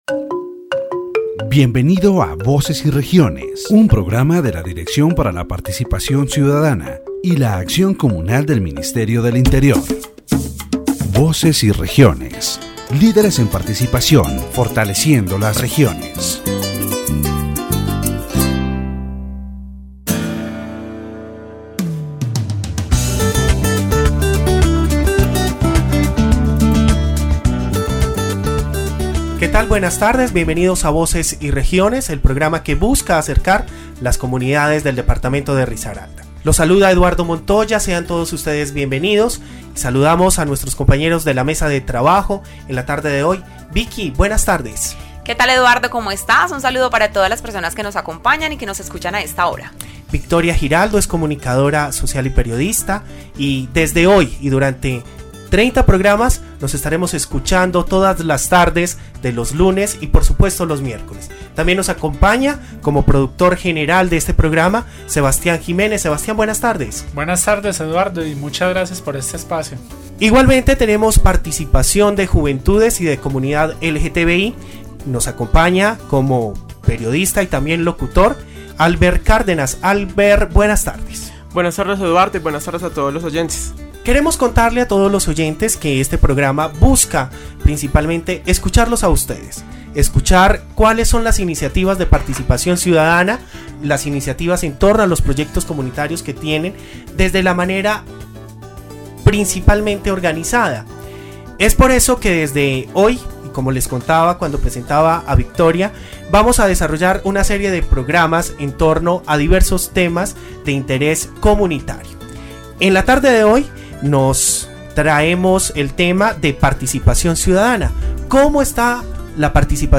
The radio program "Voices and Regions" of the Directorate for Citizen Participation and Community Action of the Ministry of the Interior, in its eleventh episode, focuses on citizen participation in the department of Risaralda.
The program features the participation of the governor of Risaralda, Carlos Alberto Botero López, who highlights the participatory budget processes and citizen meetings in the department.